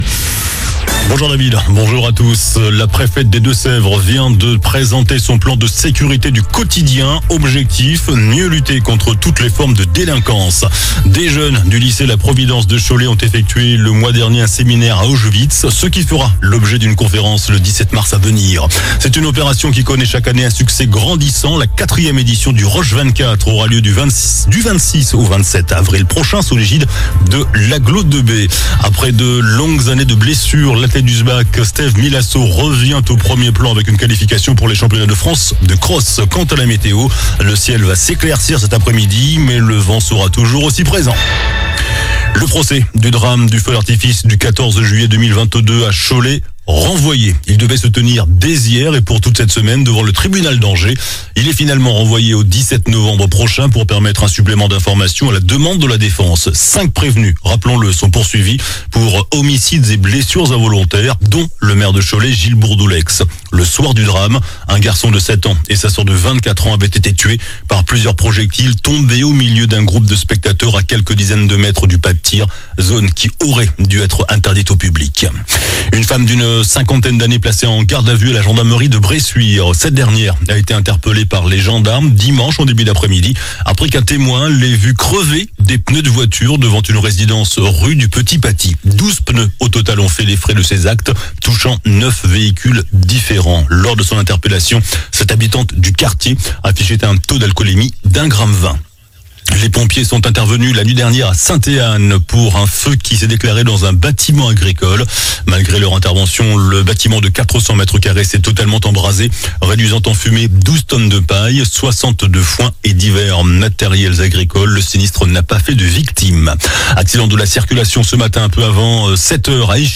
JOURNAL DU MARDI 25 FEVRIER ( MIDI )